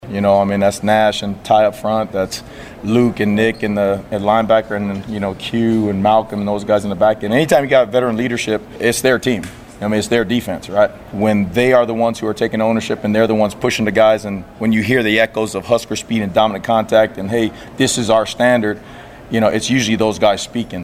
LINCOLN – Nebraska Football wrapped their final weekday practice of the second week of fall camp this morning, as a defensive emphasis was shown in the media pressers following the session.